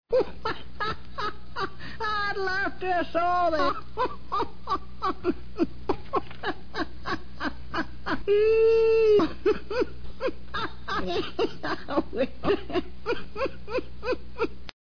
Well, actually what I mean is; listen to Abner rip through a laughing-spell.  In a hilarious episode called "The Horse", Abner visualizes Lum riding that sway-back horse he just bought and swore he would never ride again.
I can't tell if his laughing was in the script or whether he just got the ticklesI took this episode and chopped out everything but Abner's solo laughing and pieced it together as a continuous clip.